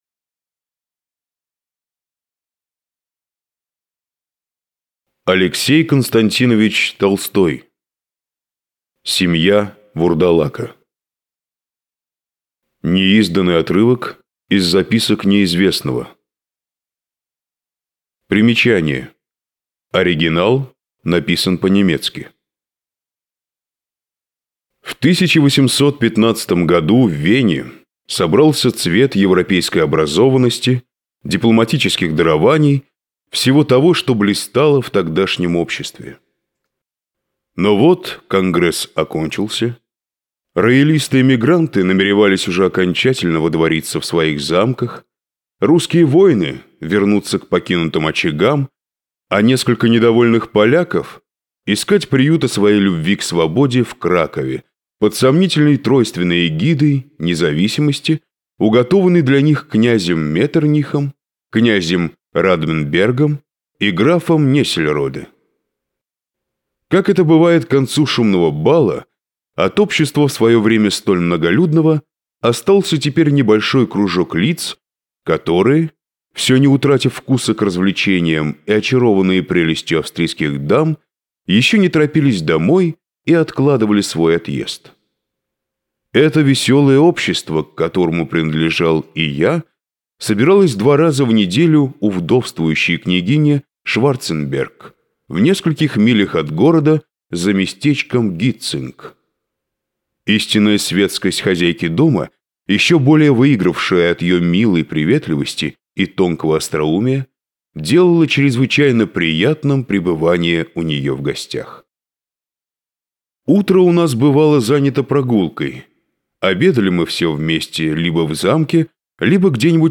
Аудиокнига Семья вурдалака - купить, скачать и слушать онлайн | КнигоПоиск